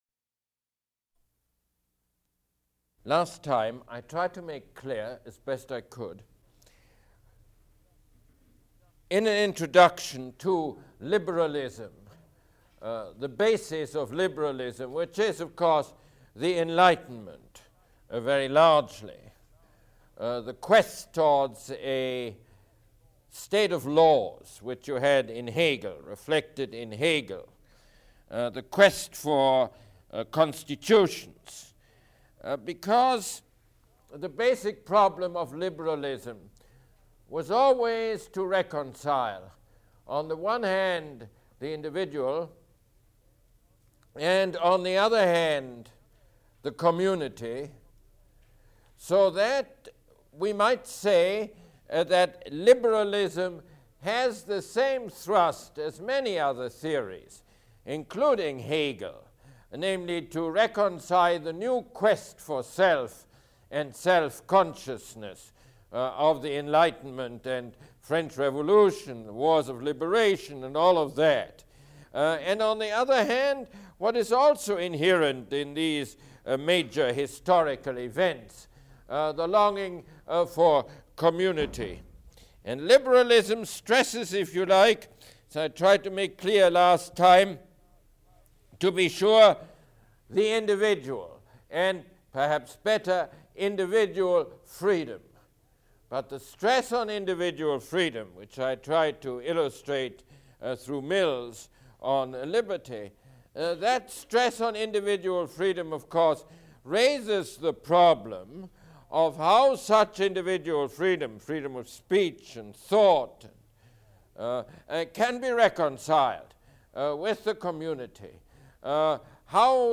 Mosse Lecture #24